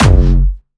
Jumpstyle Kick 9
2 F#1.wav